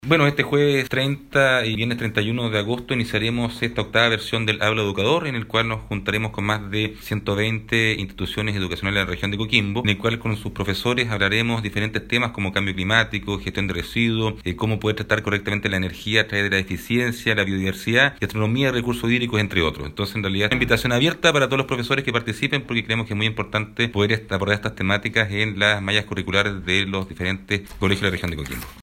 El seremi del Medio Ambiente, Cristian Felmer, explicó los alcances de la iniciativa.